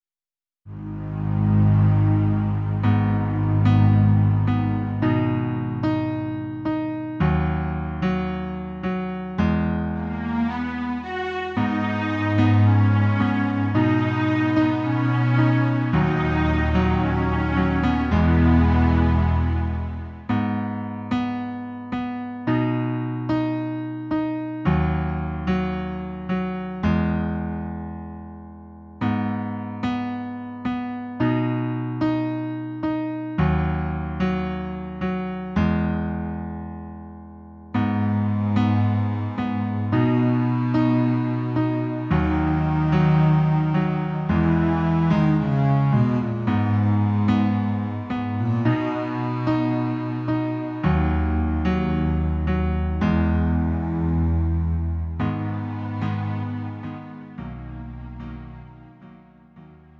음정 -1키 4:45
장르 구분 Lite MR